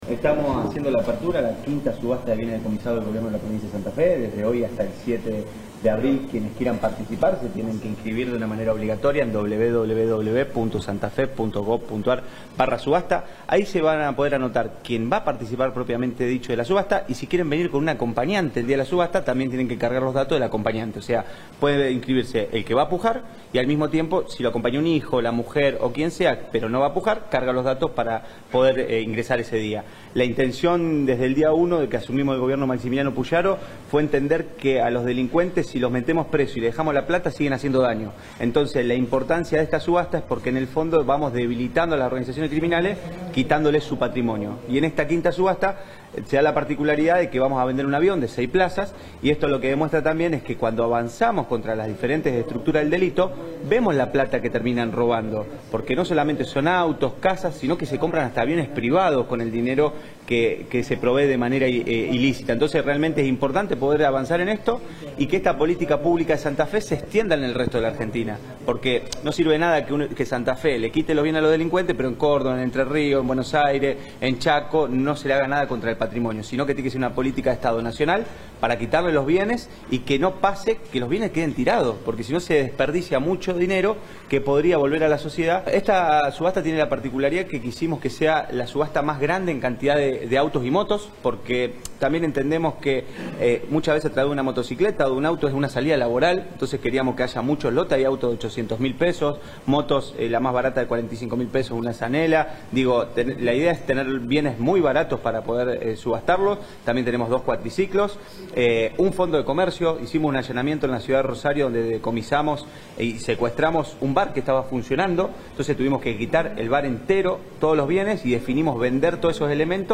Matías Figueroa Escauriza, secretario de Gestión de Registros Provinciales